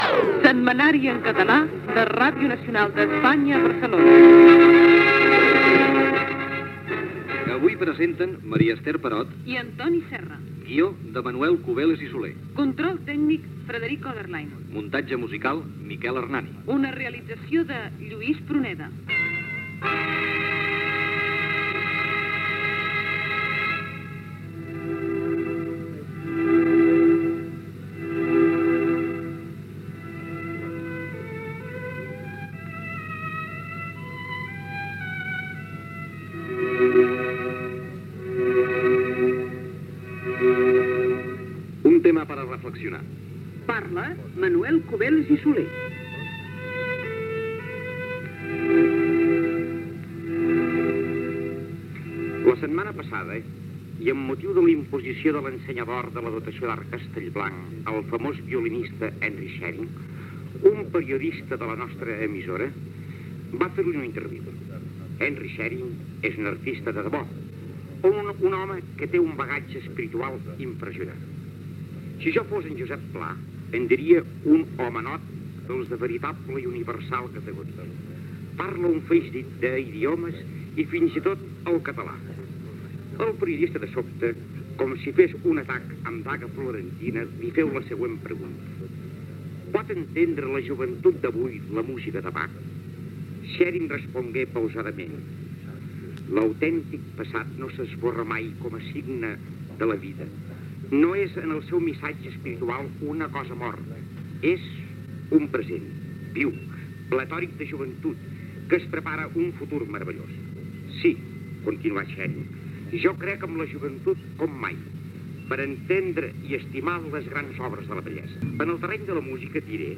Careta del programa amb els noms de l'equip.
Careta de sortida